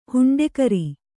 ♪ huṇḍekari